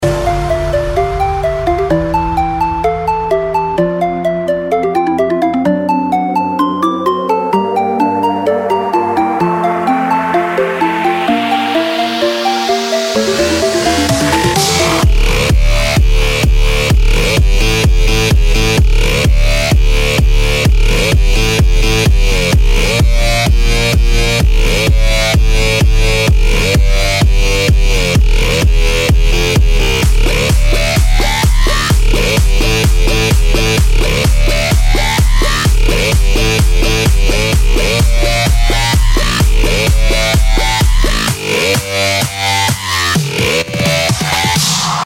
• Качество: 192, Stereo
electro house
Такое доброе начало и жёсткий конец!